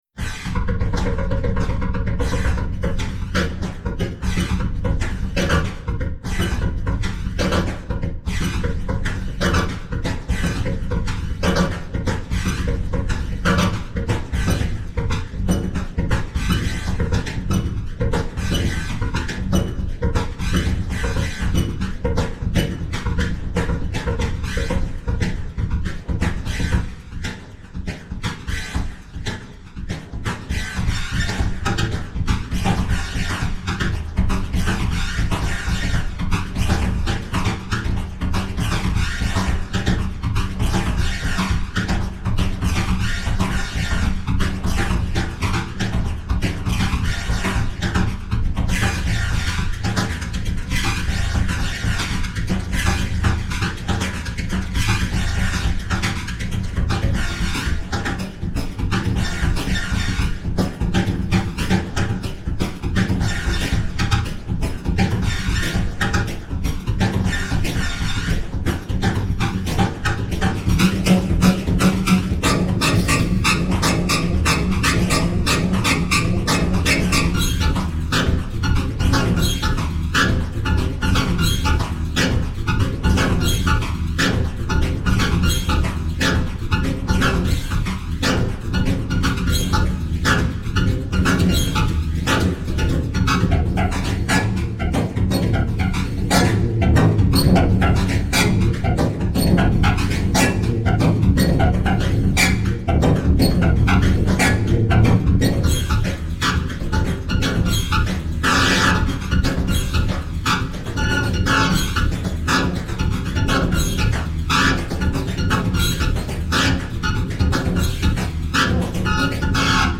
For the sake of language, What now sounds is a fat dog run after a cat_robot.